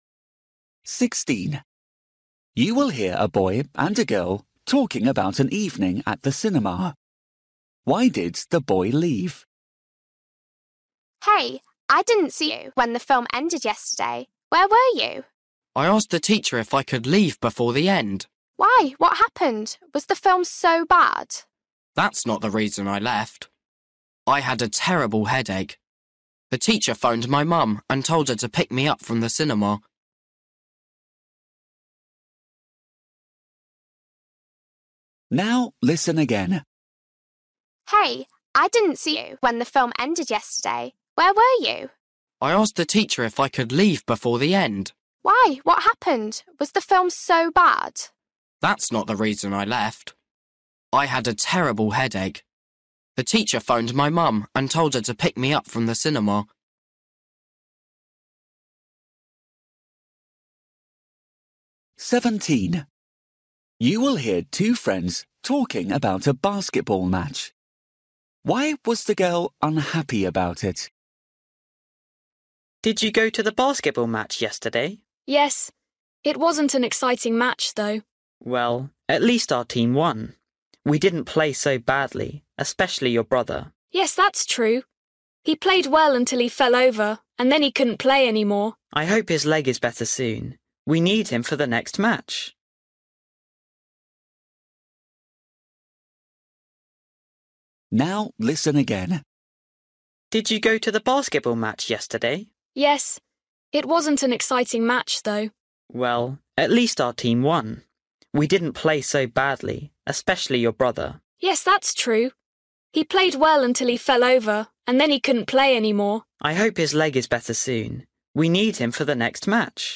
Listening: everyday short conversations
16   You will hear a boy and a girl talking about an evening at the cinema. Why did the boy leave?
17   You will hear two friends talking about a basketball match. Why was the girl unhappy about it?
18   You will hear two friends talking about a book they’ve read. They agree that.